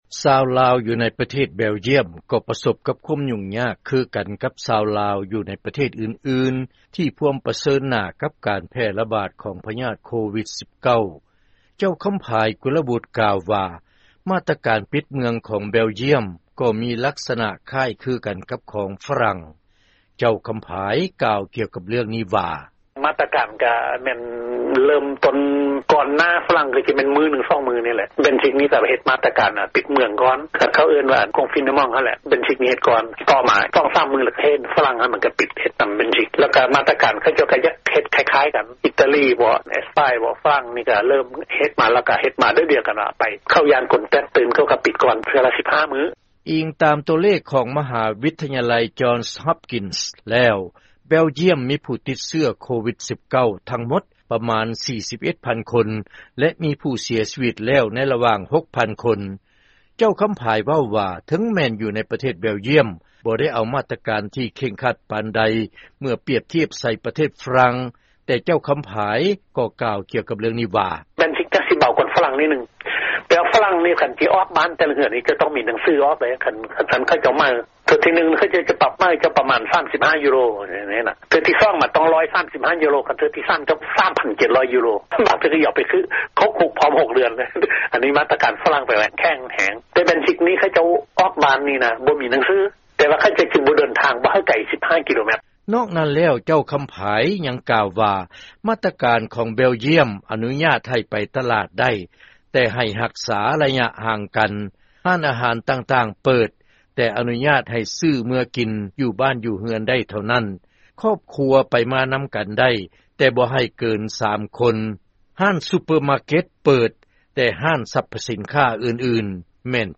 ລາຍງານ ການເອົາມາດຕະການ ຮັບມືກັບໂຄວິດ-19 ຢູ່ແບລຈ້ຽມ